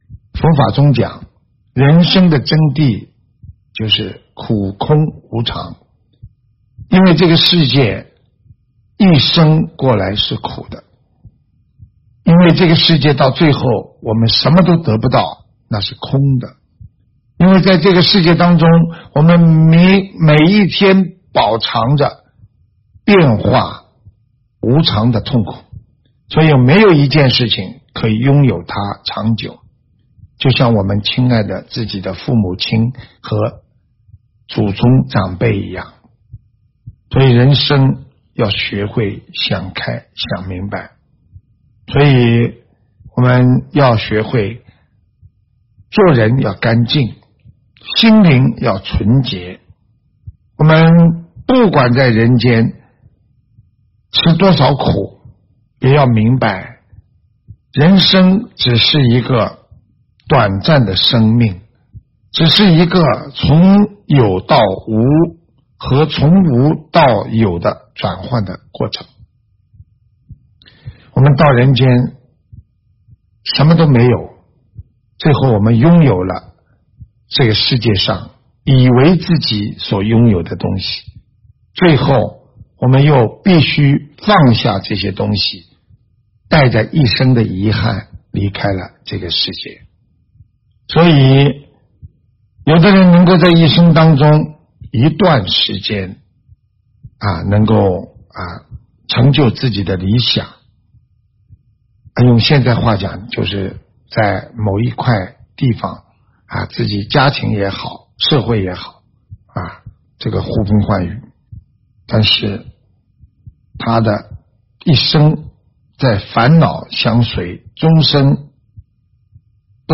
音频：人生的真谛就是苦空无常！马来西亚及台湾佛友开示！